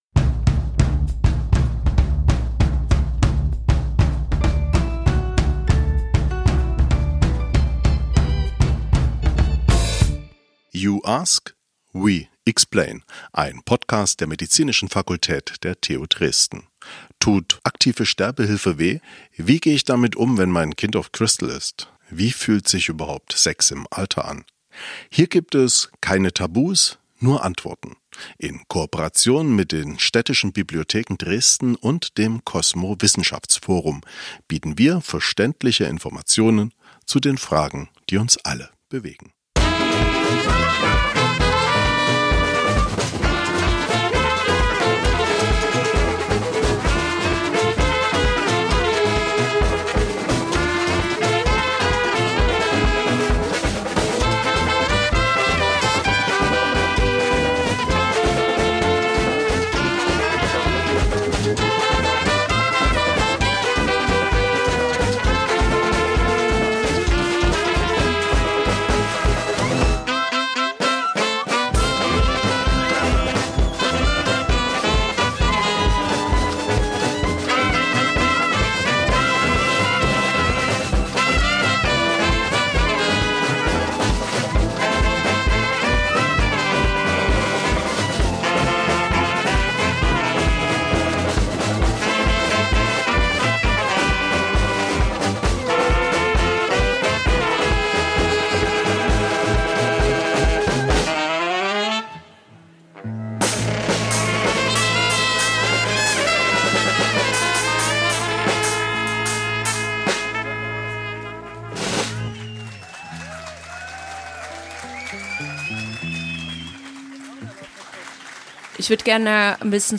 Es verspricht eine spannende Diskussion über Dresden, Europa und die Welt zu werden in der nächsten TUD Lectures+.